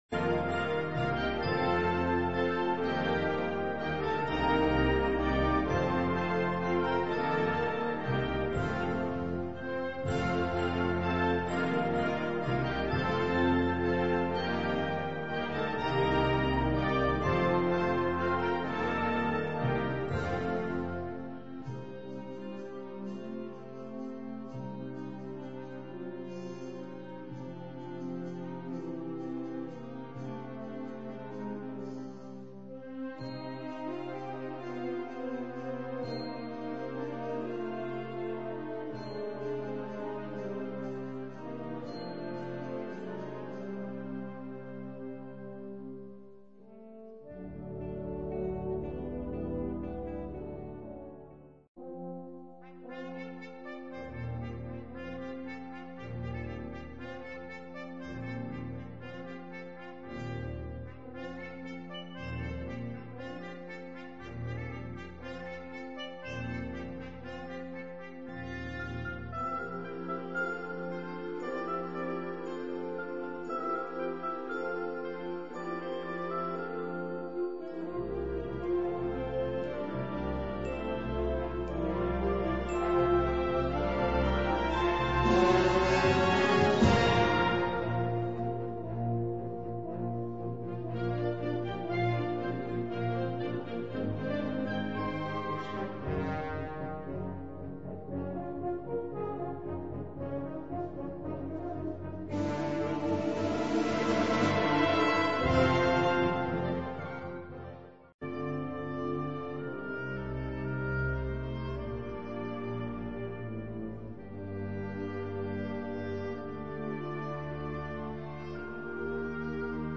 Categoria Concert/wind/brass band
Sottocategoria Suite
Instrumentation Ha (orchestra di strumenti a faito)